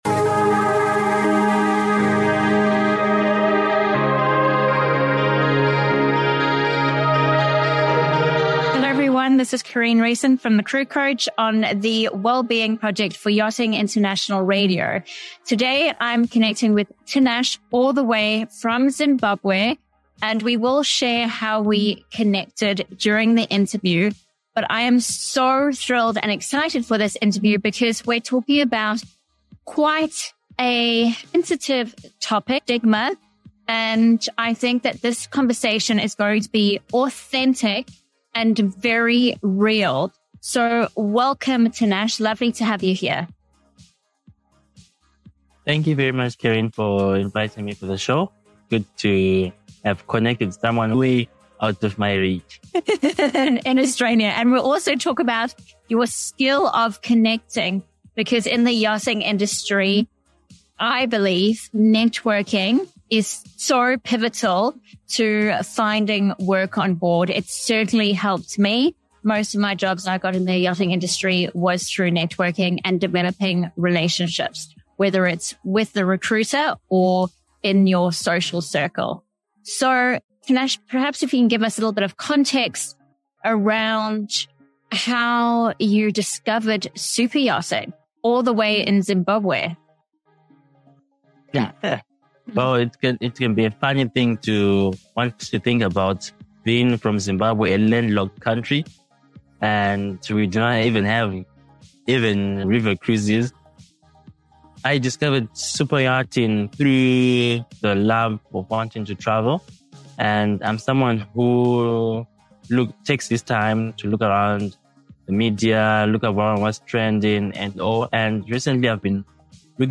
This interview serves as a beacon for dream chasers, highlighting the challenges faced and encouraging all to tap into their potential and persevere. 🚢✨ Don't miss this empowering conversation that challenges stereotypes and inspires the pursuit of dreams in the yachting industry!